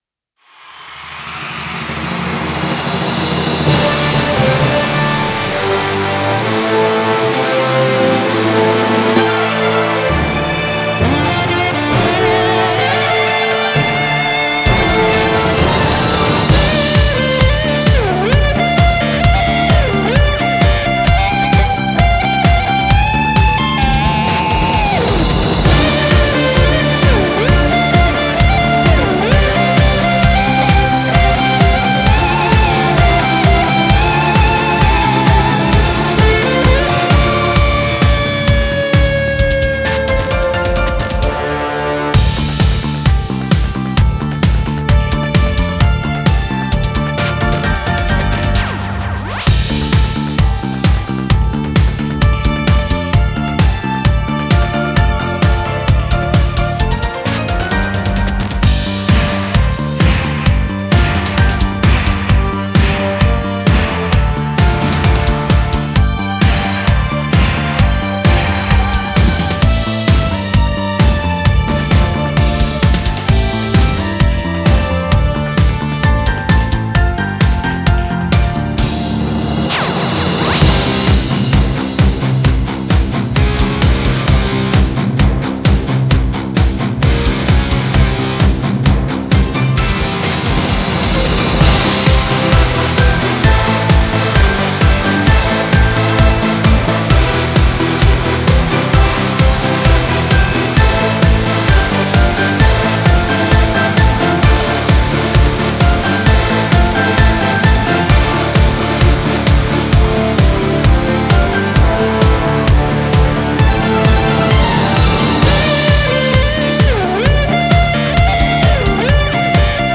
04-KARAOKE
Ηλεκτρική Κιθάρα